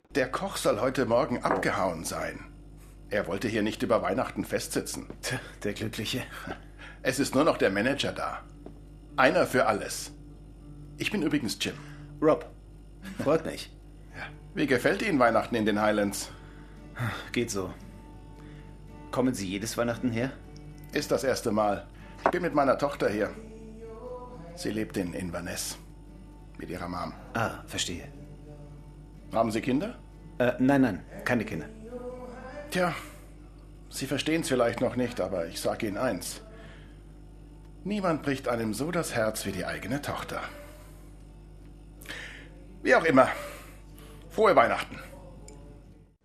Sprachproben
Männlich
Mezzo-Sopran / Bariton / Mittel
Alt / Bass / Tief
Aber auch Sachtexte z.B. für Imagefilme, E-Learning, Werbung oder Dokumentationen, erwecke ich durch meine warme, sonore, vertrauenerweckende Stimme zum Leben.